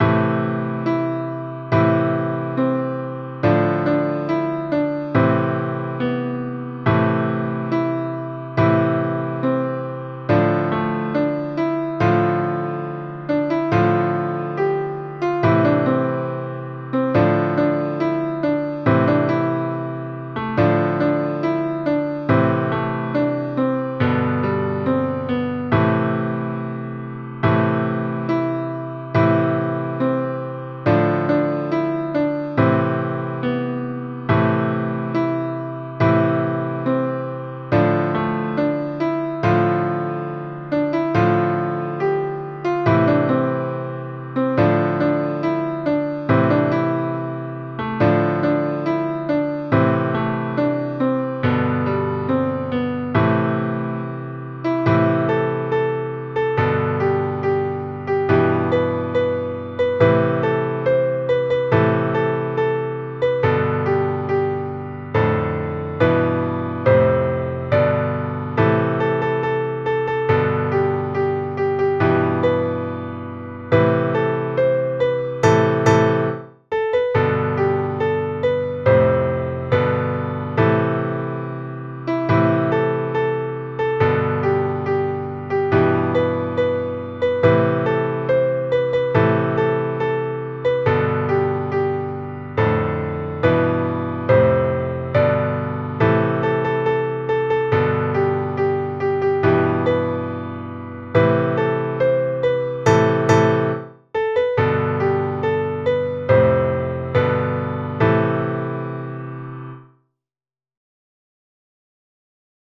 Jewish Folk Song
Piano Arrangement
A minor ♩= 70 bpm